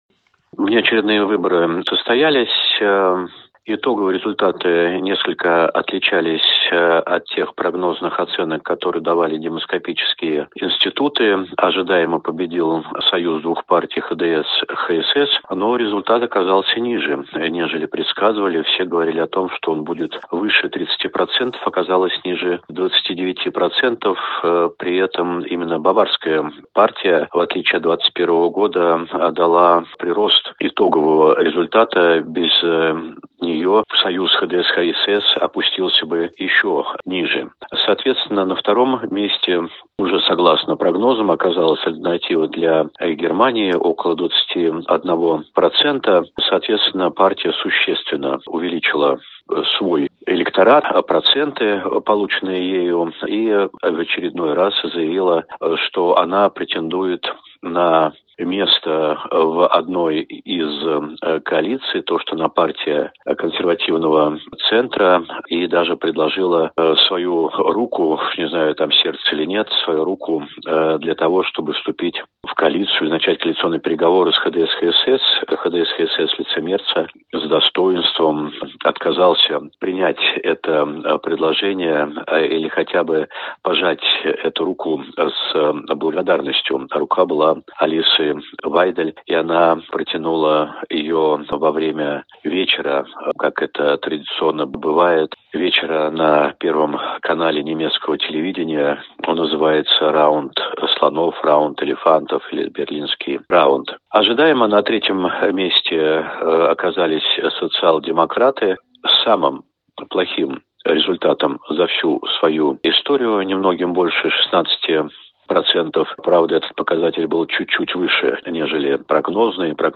аудиоверсия программы